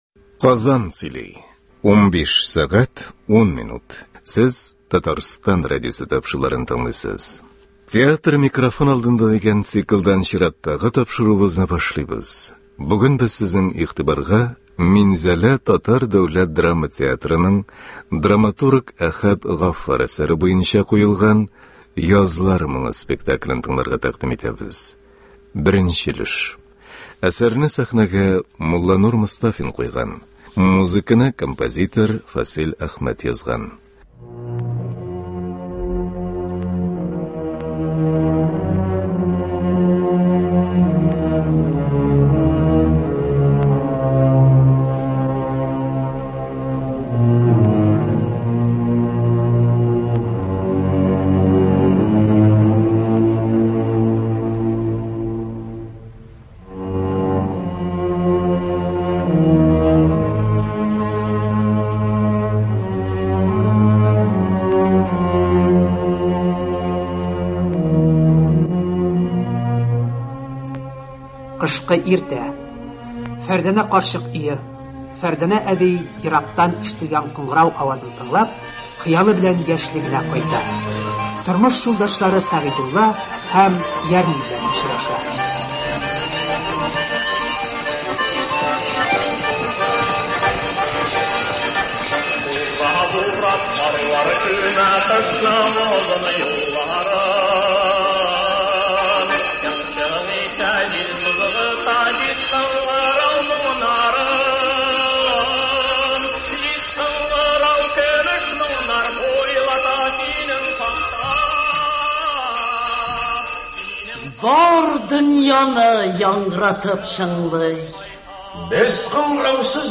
“Язлар моңы”. Минзәлә ТДДТ спектакленең радиоварианты.